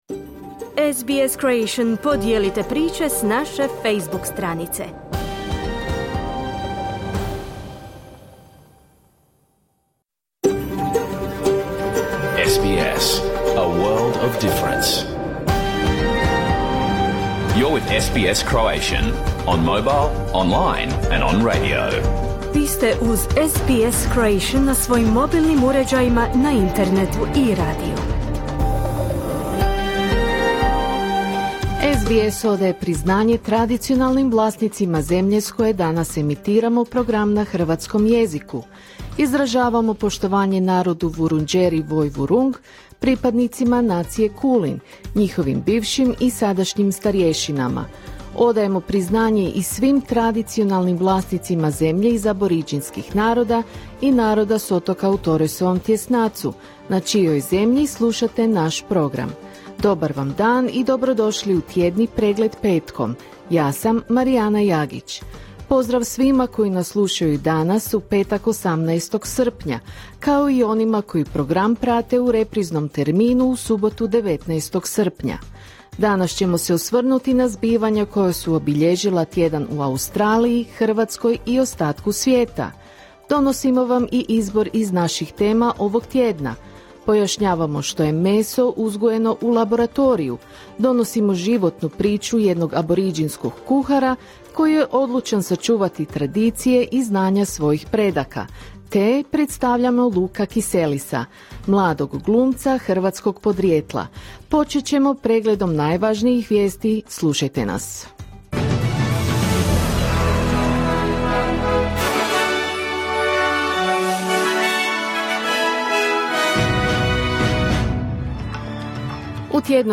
Vijesti, aktualne teme i razgovori iz Australije, Hrvatske i ostatka svijeta.